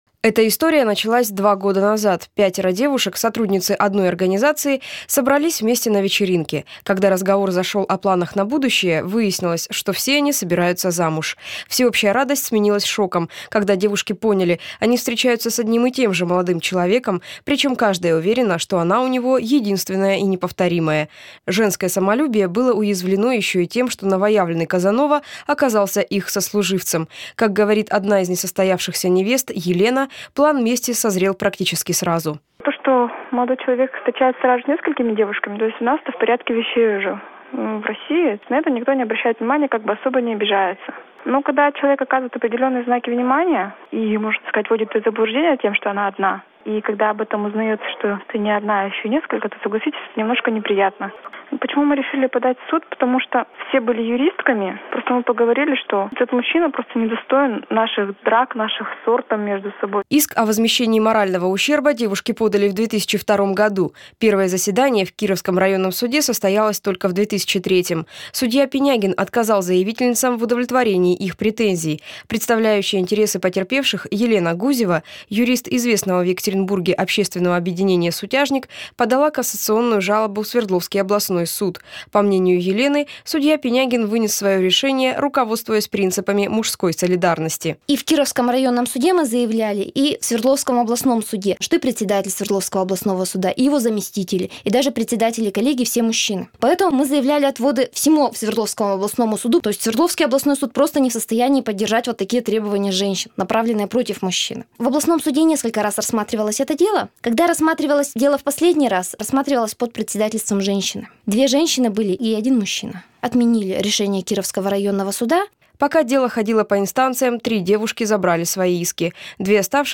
Вы здесь: Главная / Библиотека / Интервью сутяжников / Интервью